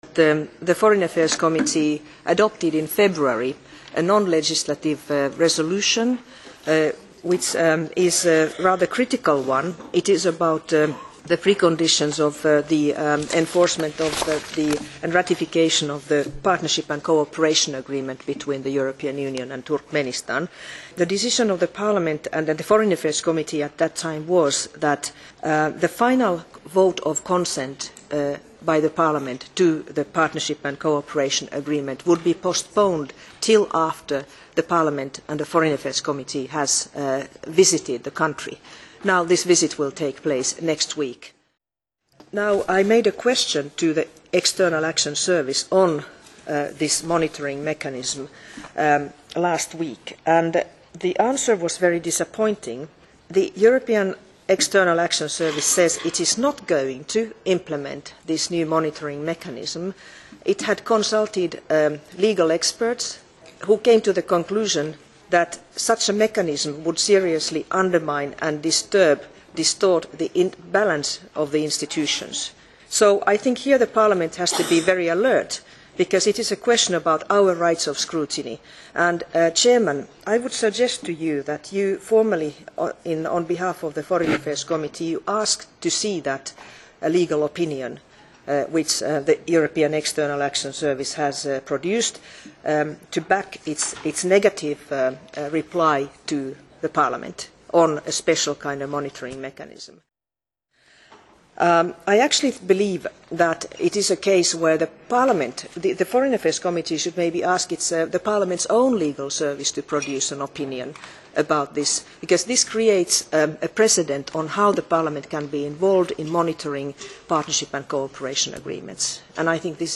Heýdi Hautulanyň deňlenişikdäki çykyşyndan bölekler (20-nji aprel, Brýussel)